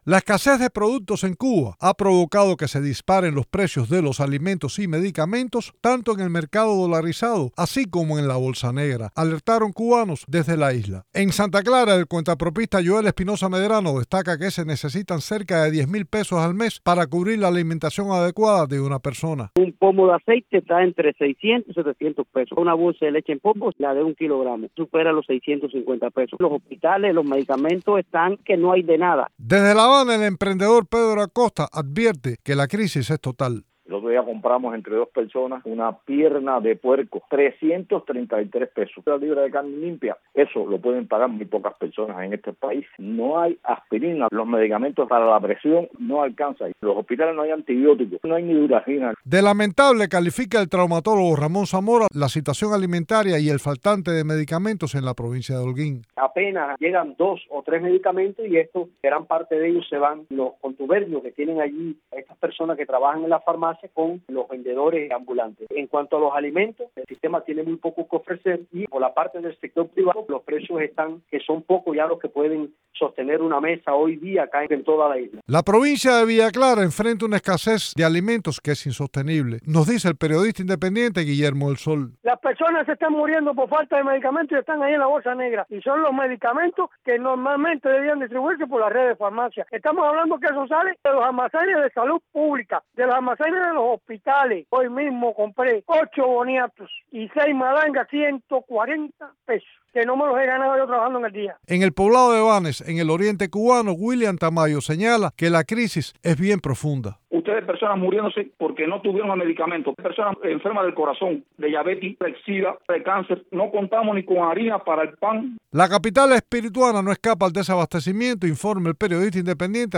La escasez de productos en Cuba ha provocado que se disparen los precios de los alimentos y medicamentos, tanto en los comercios en moneda libremente convertible (MLC) como en el mercado negro, alertaron a Radio Televisión Martí cubanos residentes en la isla.